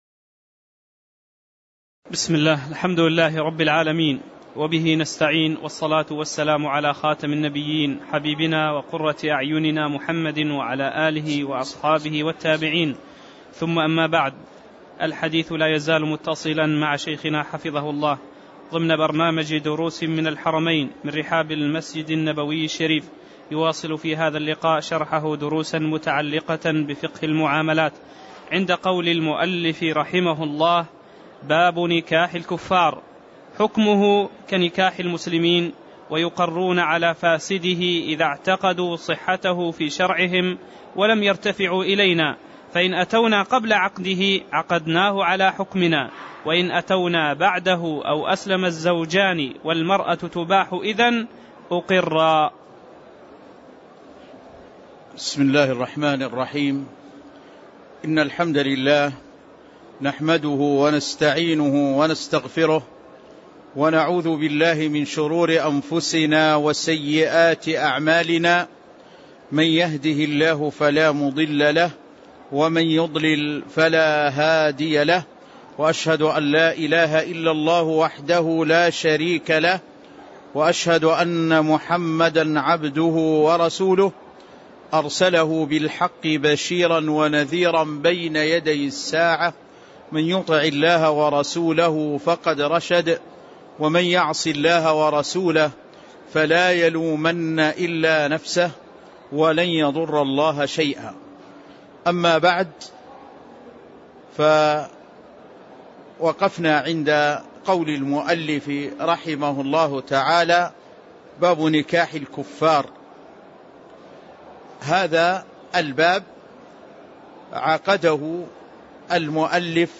تاريخ النشر ١٤ جمادى الأولى ١٤٣٧ هـ المكان: المسجد النبوي الشيخ